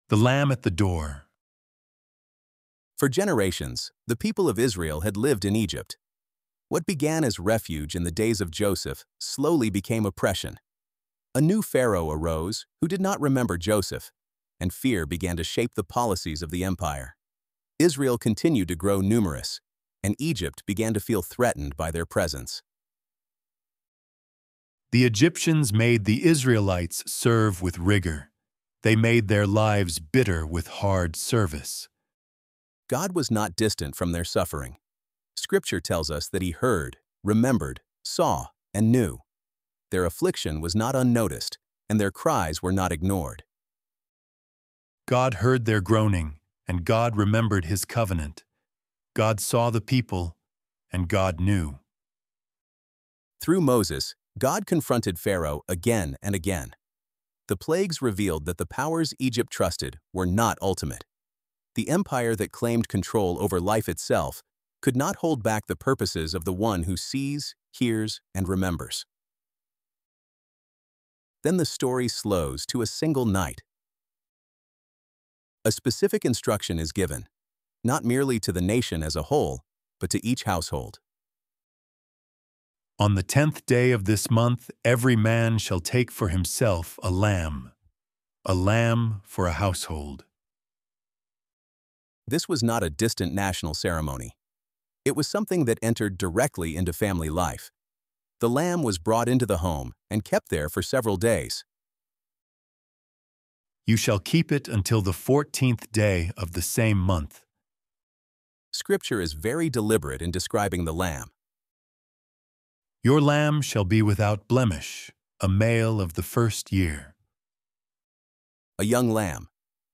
ElevenLabs_The-Lamb-At-The-Door-2.mp3